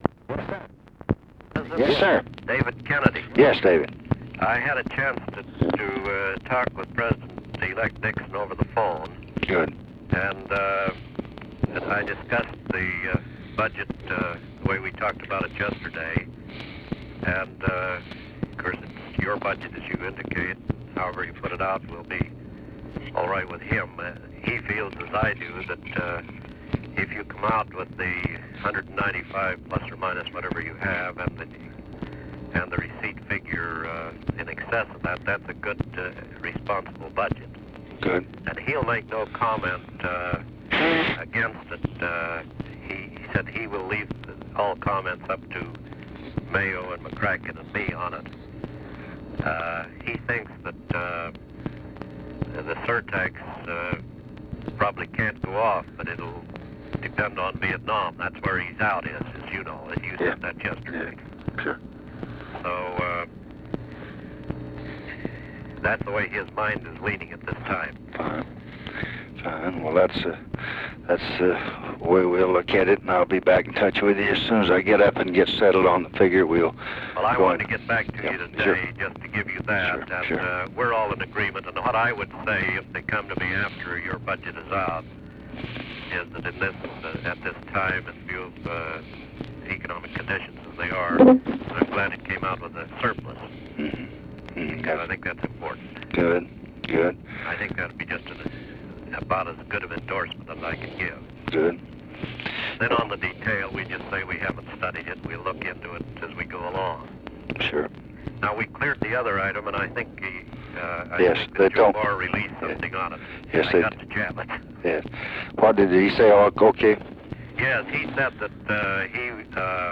Conversation with DAVID KENNEDY, January 1, 1969
Secret White House Tapes